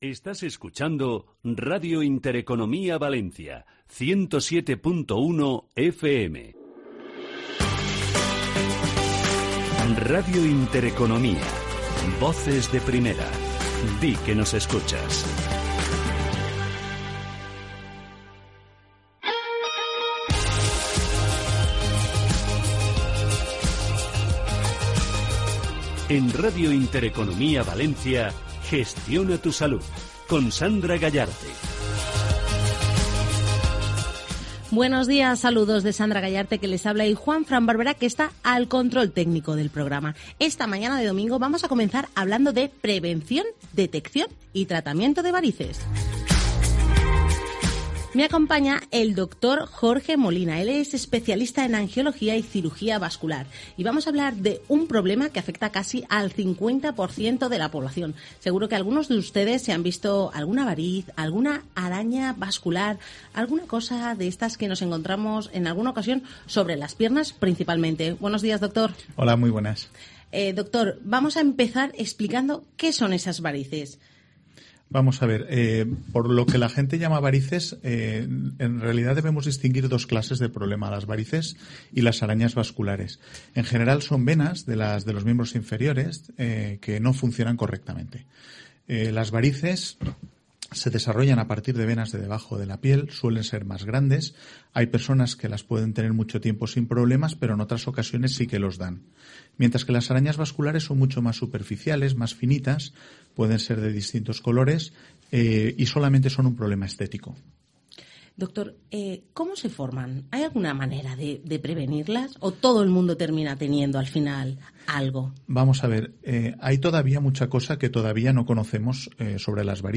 Entrevista en Radio Intereconomía Valencia
Entrevista-Radio-Intereconomia.mp3